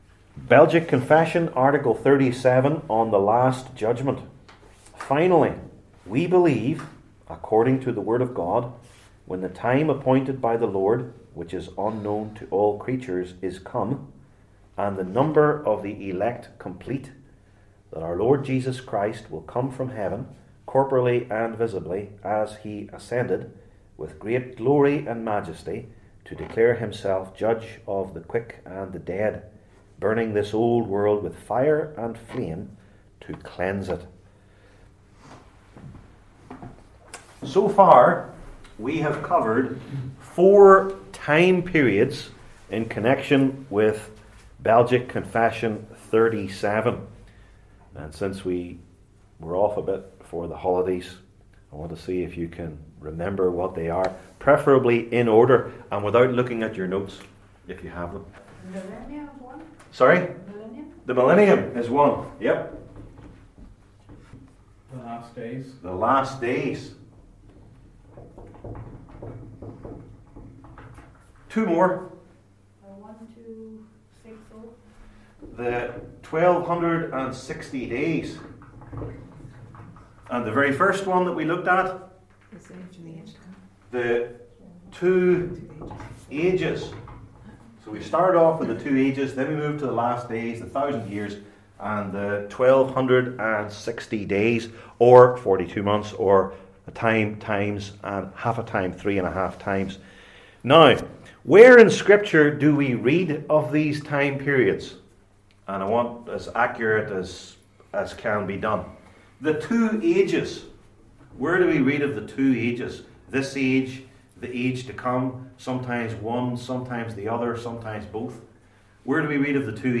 Passage: Romans 13 Service Type: Belgic Confession Classes